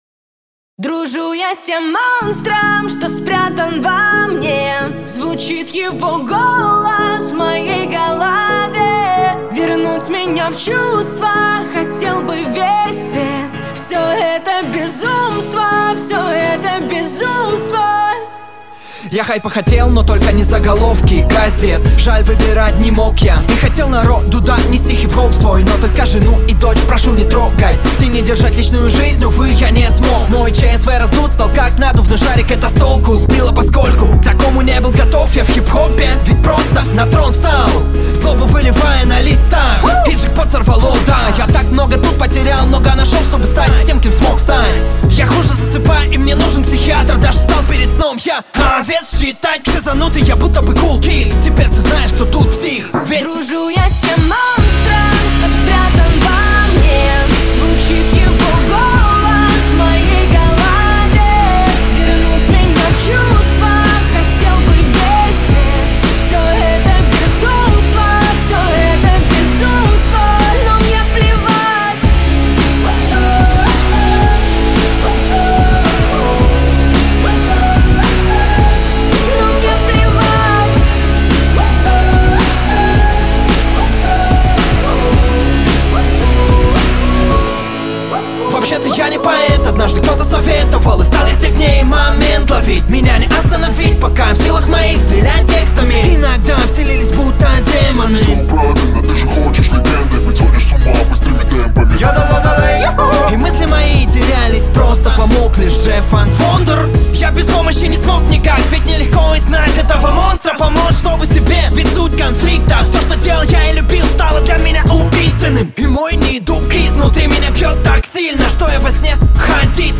кавер на Русском